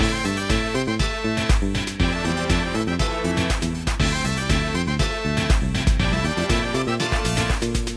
Source AUX cable connected from my 3DS to my PC.